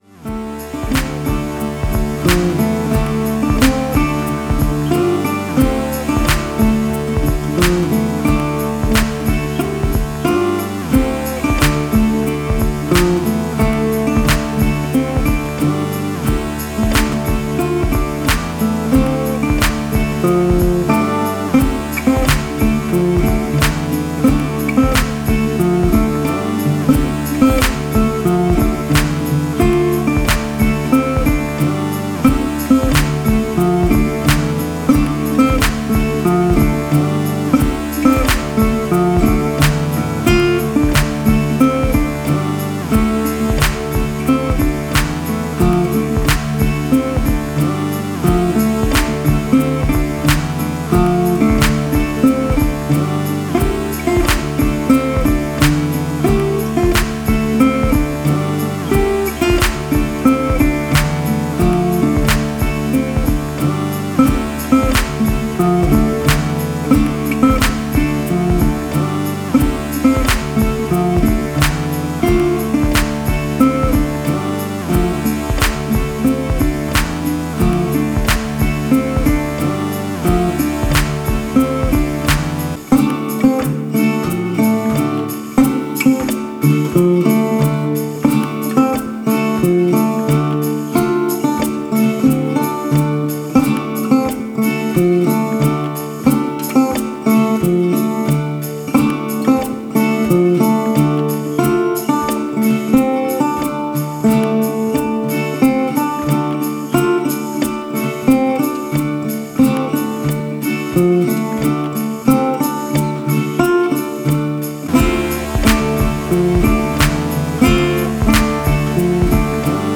Blues Gitarre mit pulsierendem langamen loop und synth.